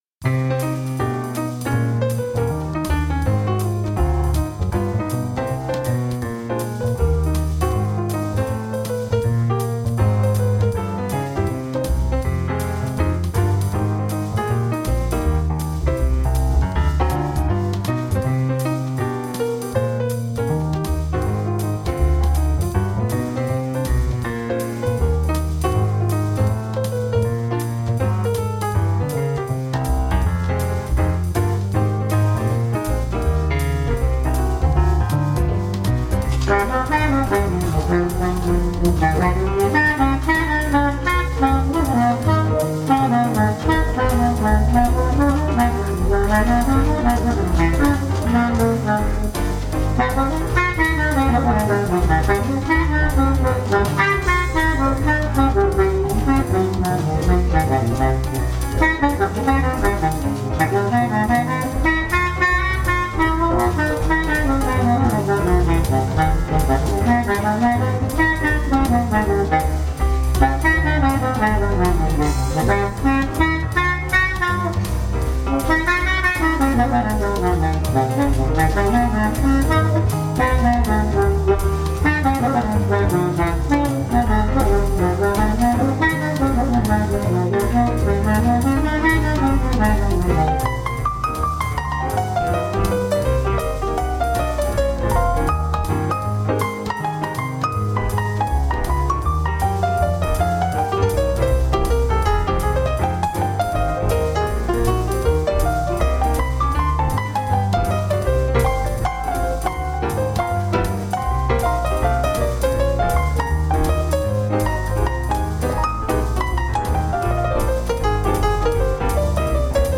Toe teasin' jazz tunes for all occasions.